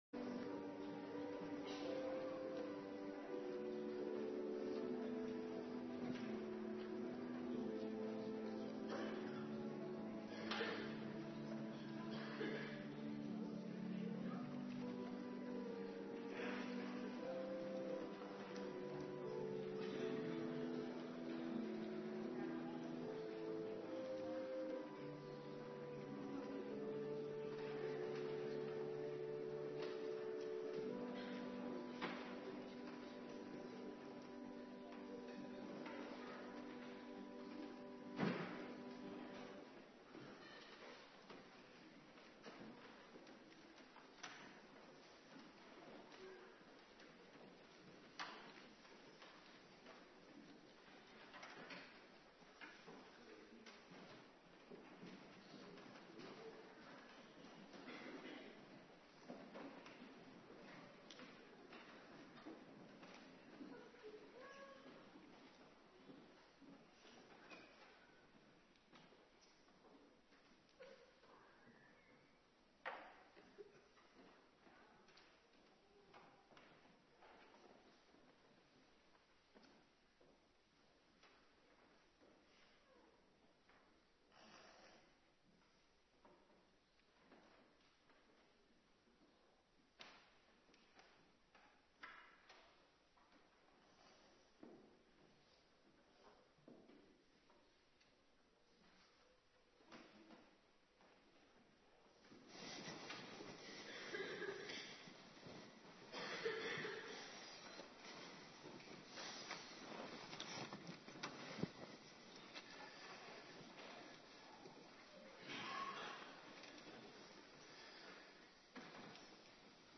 Morgendienst Heilig Avondmaal
09:30 t/m 11:00 Locatie: Hervormde Gemeente Waarder Agenda